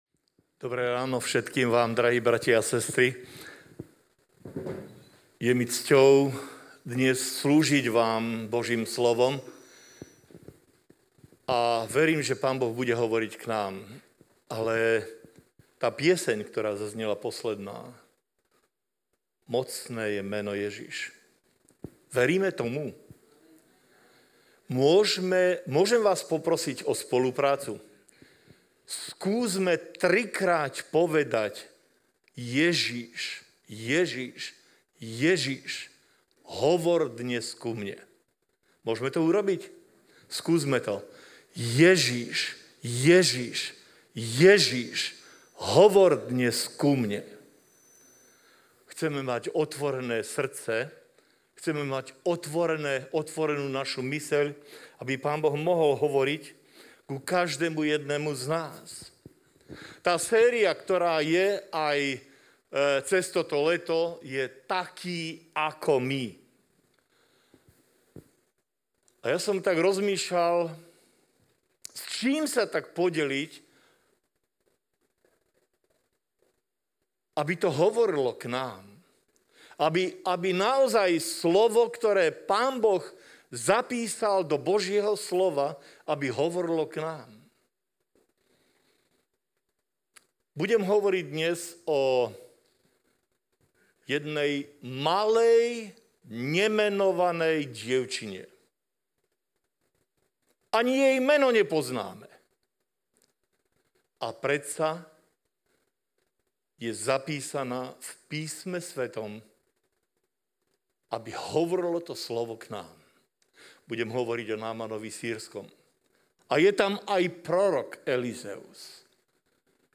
Dnes sa v rámci letnej série kázní s názvom „TAKÍ, AKO MY“ pozrieme na príbeh Námana Sýrskeho a proroka Elizea. Naučme sa spolu z ich príbehu aj my to, ako počúvať na Boží hlas aj keď sa môže zdať, že Boh hovorí cez neočakávané veci či osoby.